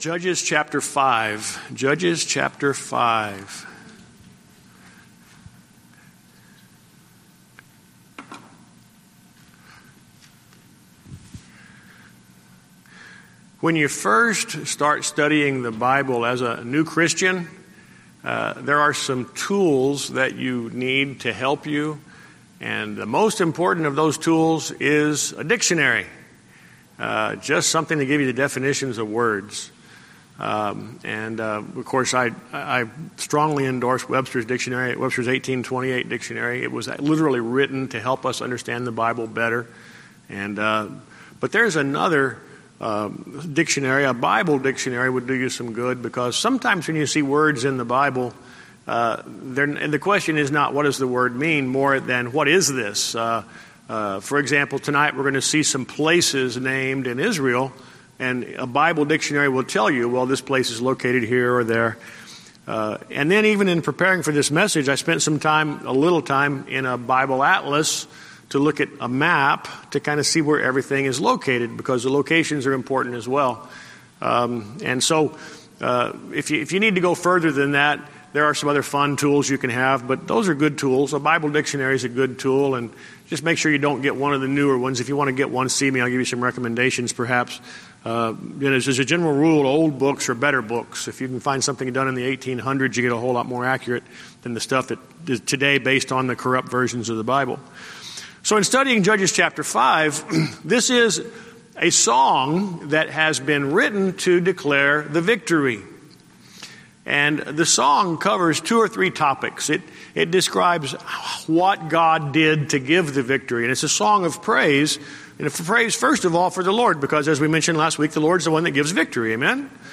Sermon Recordings